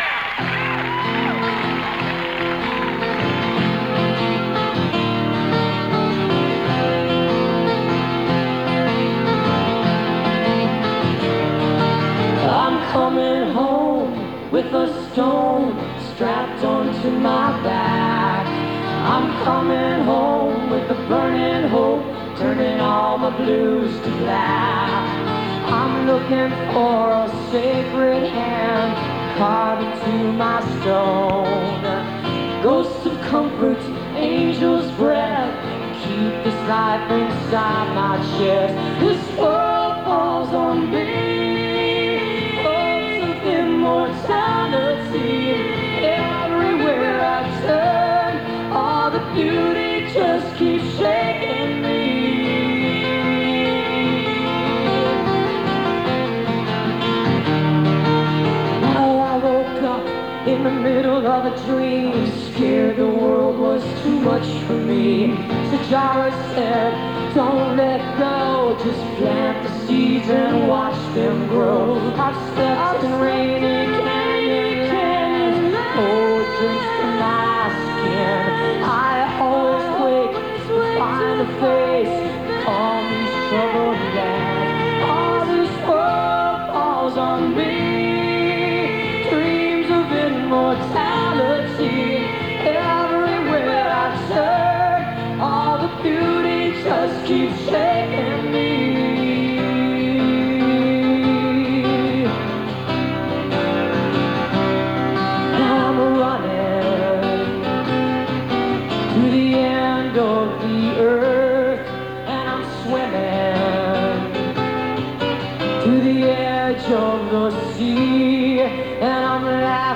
(acoustic duo show)
(radio broadcast)